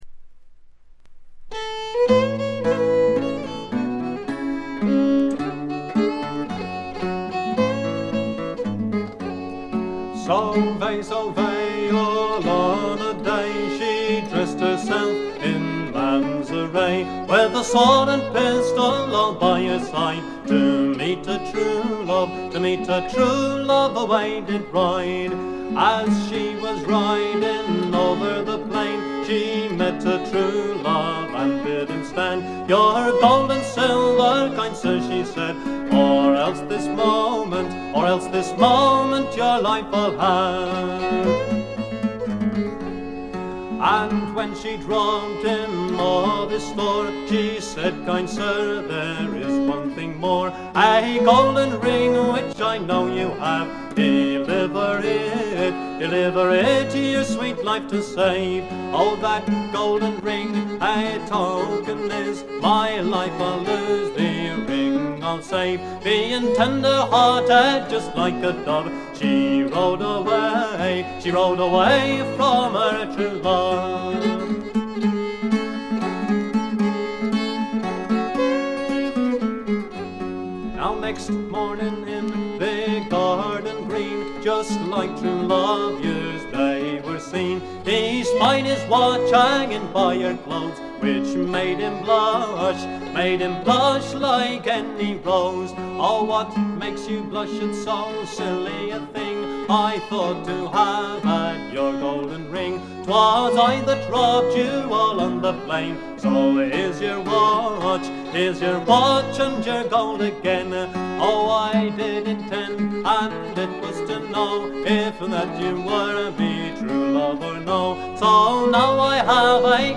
ところどころで軽微なチリプチ。目立つノイズはありません。
英国フォーク必聴盤。
Stereo盤。
試聴曲は現品からの取り込み音源です。